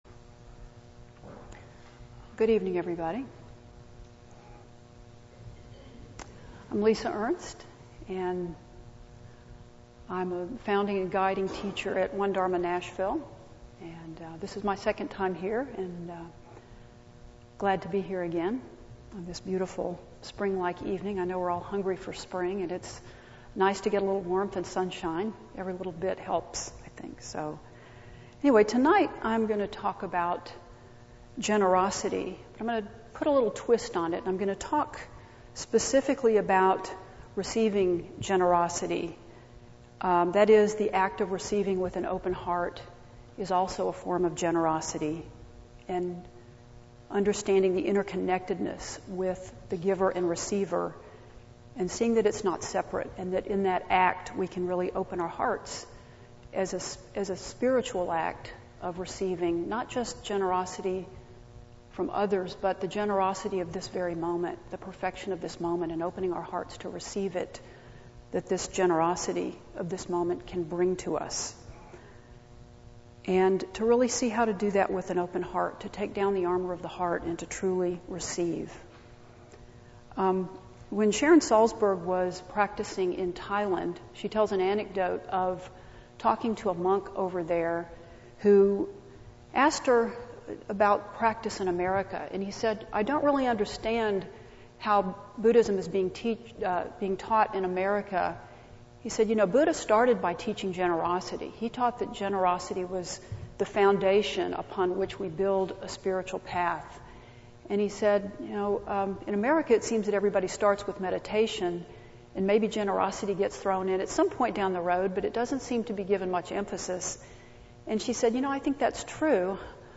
This dharma talk was recorded at Insight Nashville on March 19, 2014. I explore the parami of generosity, especially in the context of receiving as an interconnected act of lovingkindness. The full audio is 30 minutes; the final portion is a guided meditation on opening our hearts to receive as well as give metta.